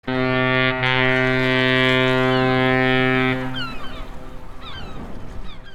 Schiffshorn klingelton kostenlos
Kategorien: Soundeffekte